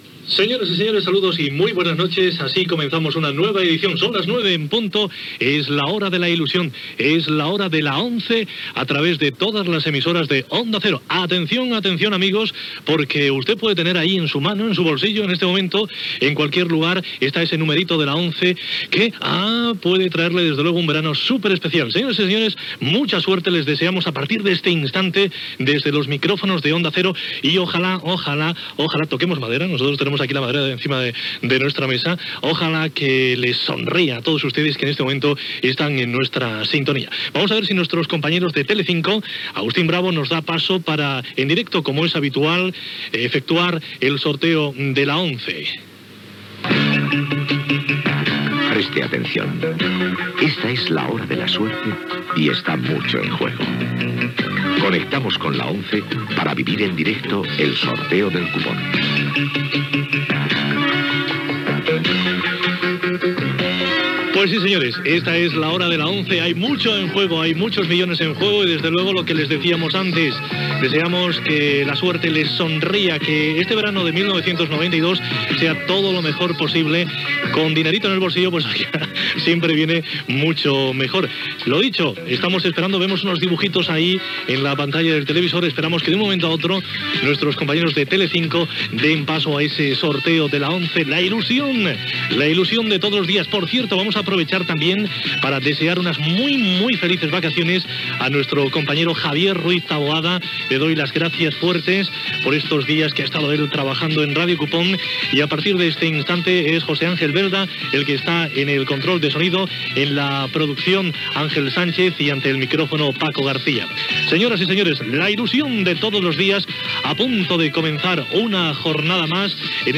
Hora, transmissió del sorteig del cupó de l'ONCE fet des de Telecinco. Equip, números premiats en dies passats, premis i número guanyador del dia. Careta dels Serveis informatius, sumari, modificiació de l'article 13.2 de la constitució espanyola, informe Filesa, tirotejos a França i Estats Units, connexió amb la novellada de la fira taurina d'Algeciras.
Gènere radiofònic Informatiu